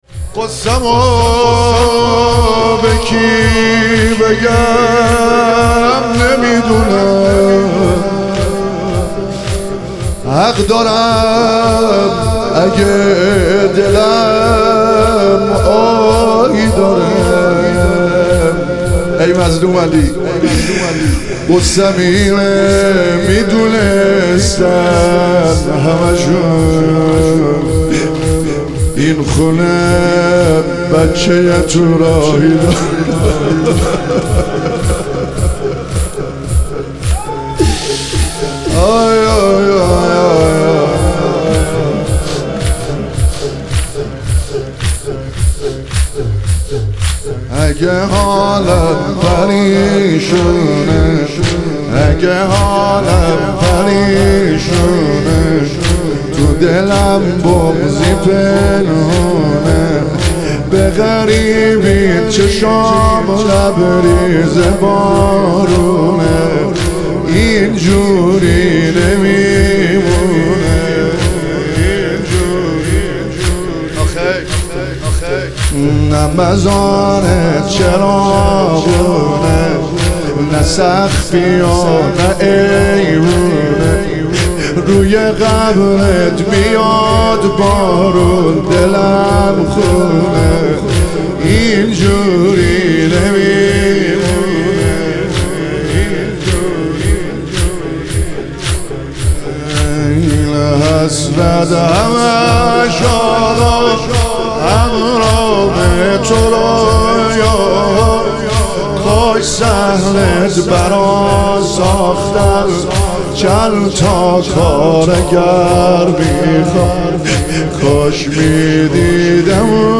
تخریب بقیع - تک